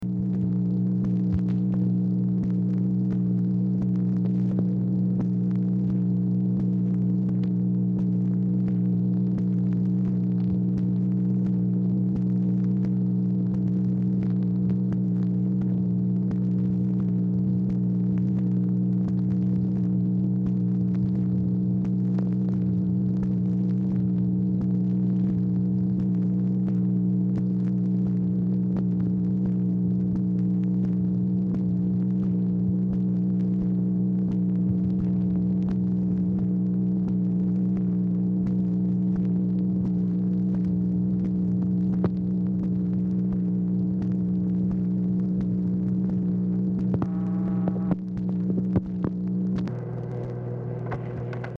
Telephone conversation # 4354, sound recording, MACHINE NOISE, 7/27/1964, time unknown | Discover LBJ
Format Dictation belt